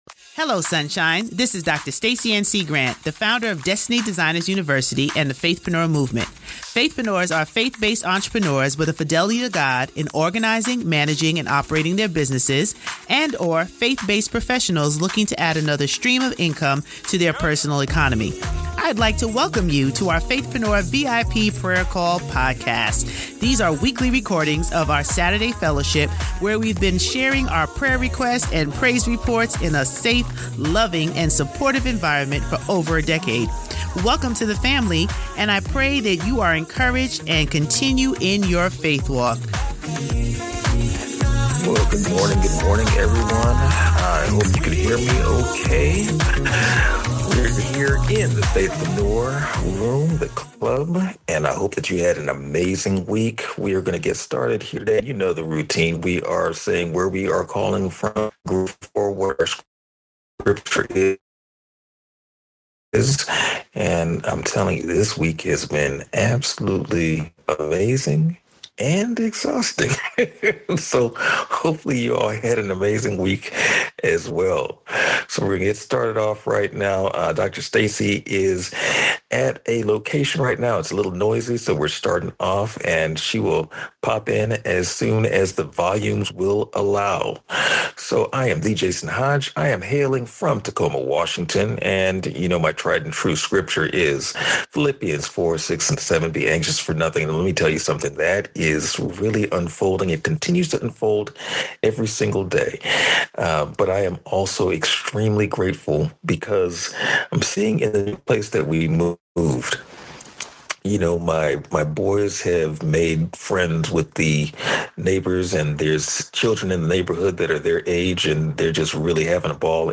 These are recordings of our weekly Saturday’s fellowship where we have been sharing our prayer requests & praise reports in a safe, loving & supportive environment for over a decade.